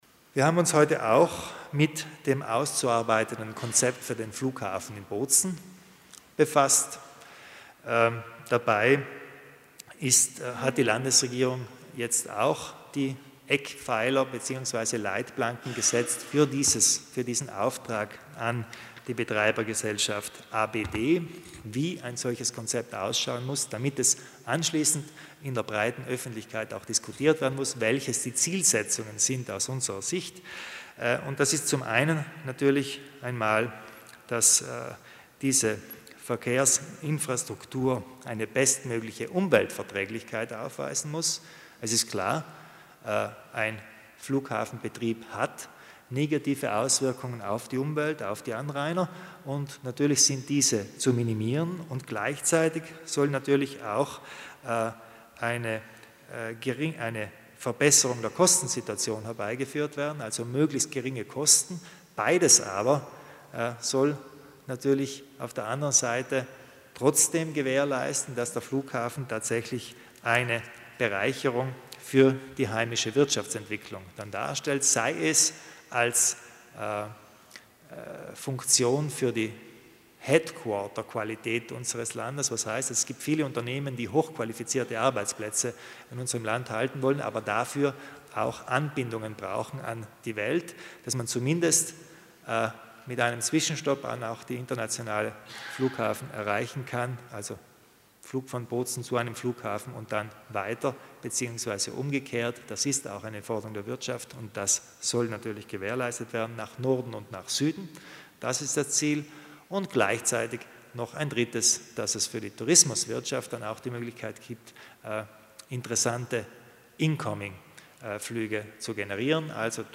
Landeshauptmann Kompatscher zur Zukunft des Flughafens